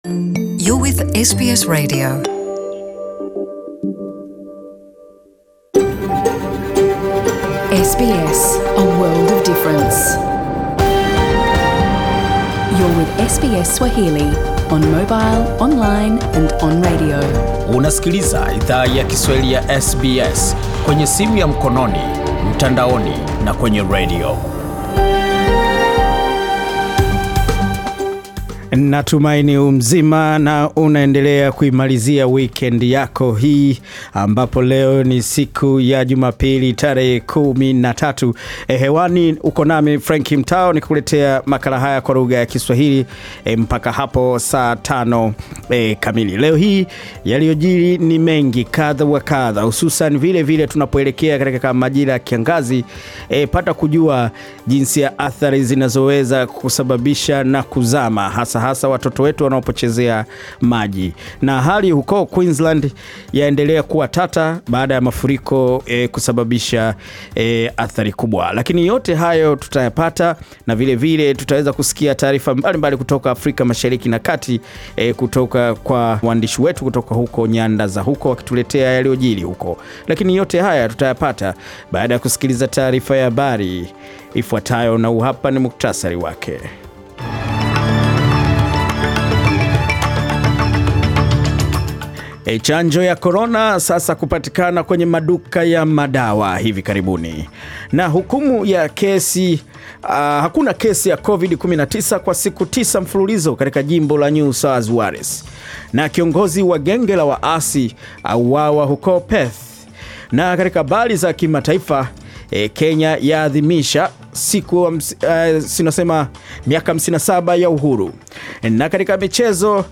Taarifa ya habari 13 Disemba 2020